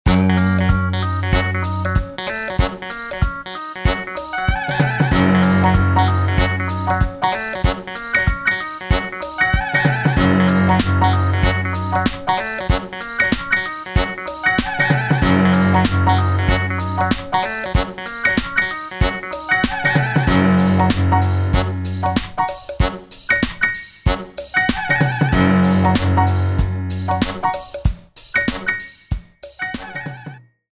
blues_01.mp3